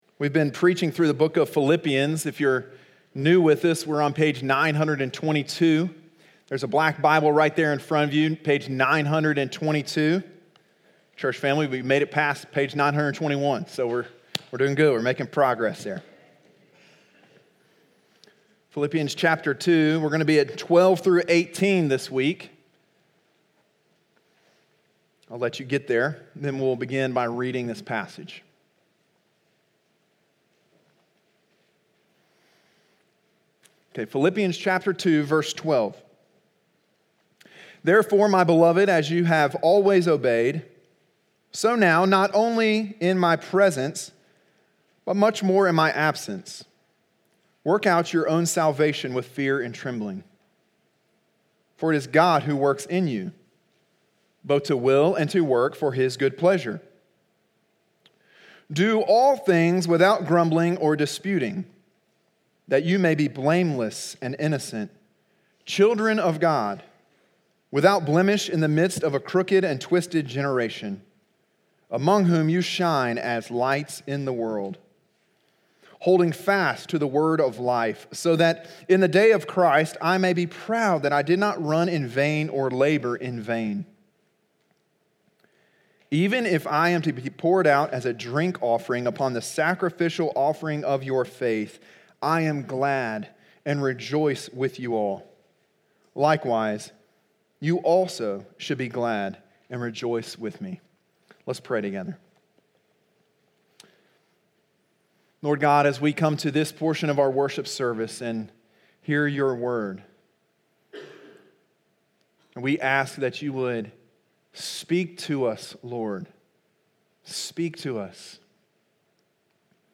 sermon9.01.19.mp3